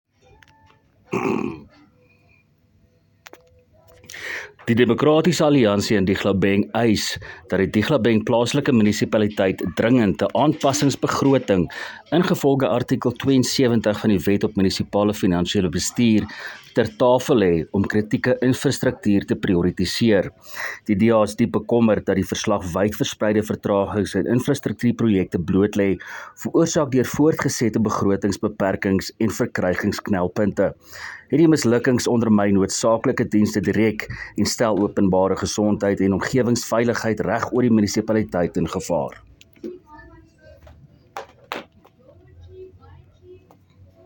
Afrikaans soundbite by Cllr Willie Theunissen